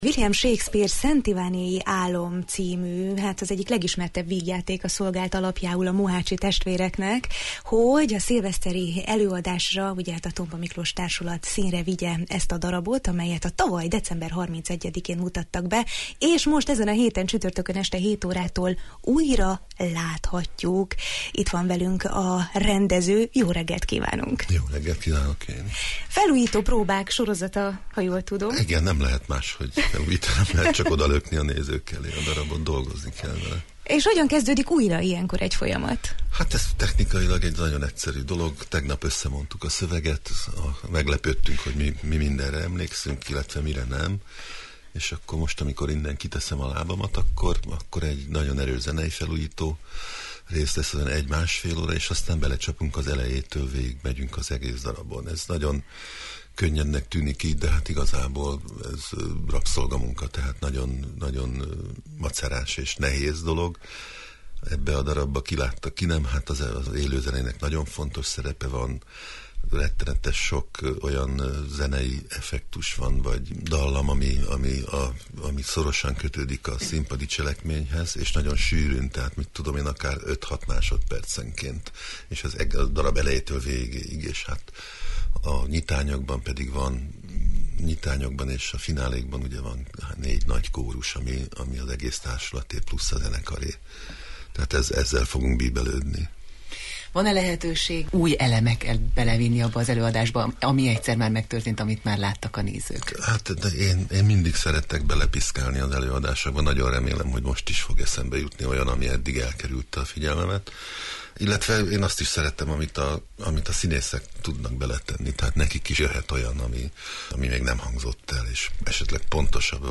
Az előadást újragombolva szeptember 28-án láthatja a nagyérdemű közönség, ezt megelőzően a Jó reggelt, Erdély!-ben vendégünk volt a rendező, Mohácsi János, aki nemcsak az előadásról, de rendezői elveiről is beszélt a hallgatóknak.